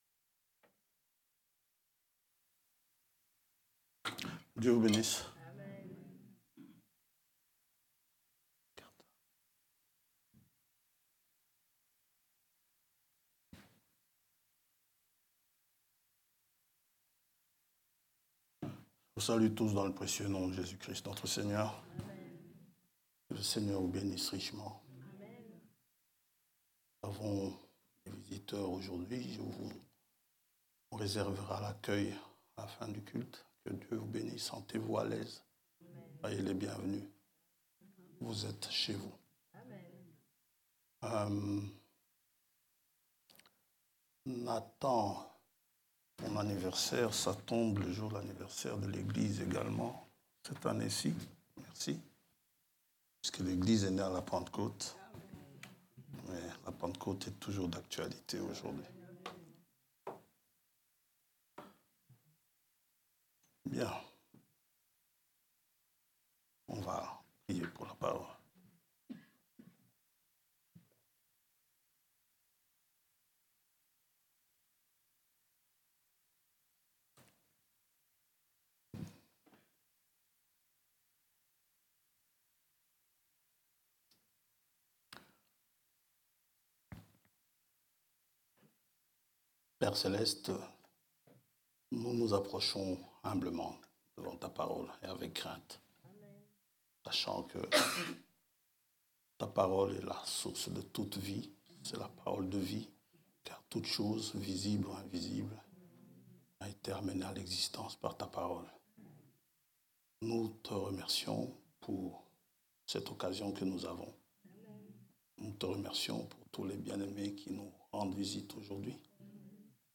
Catégorie: Prédications